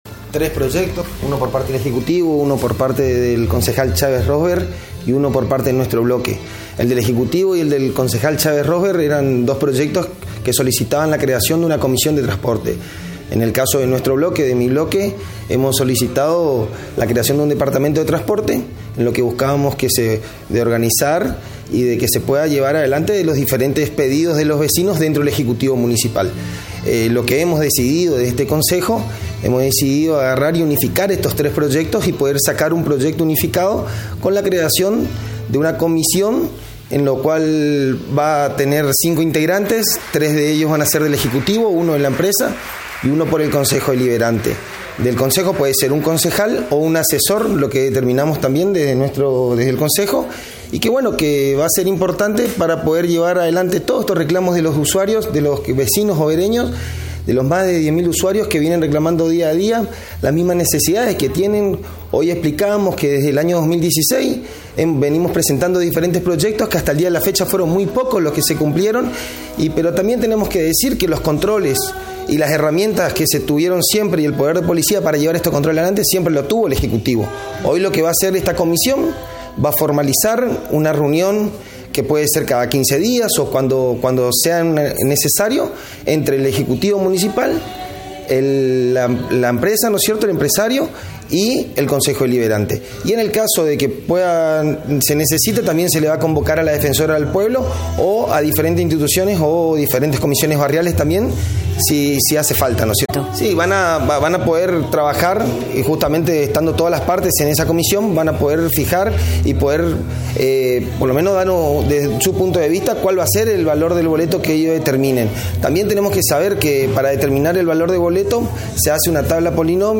Alejandro Etchberger -Concejal PJ